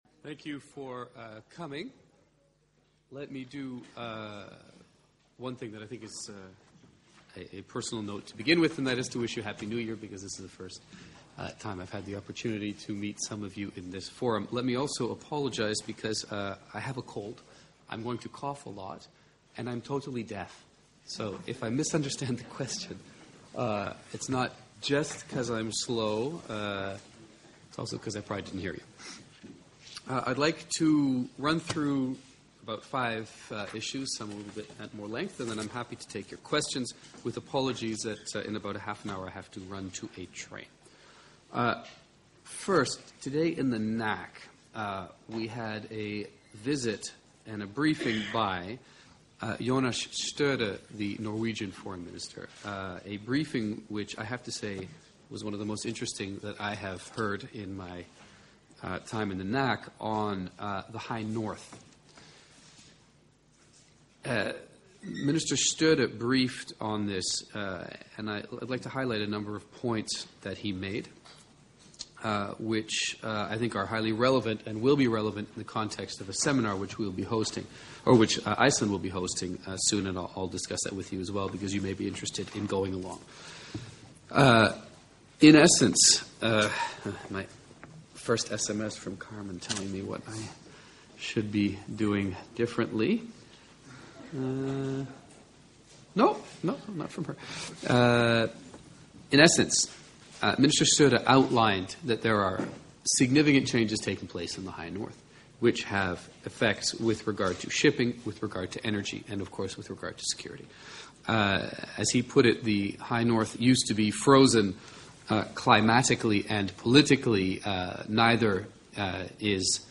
In his first press briefing with journalists in 2009, NATO Spokesman James Appathurai presented the recently issued Tactical Directive as a key step in ISAF’s efforts to avoid civilian casualties.
Audio Press briefing by NATO Spokesman, opens new window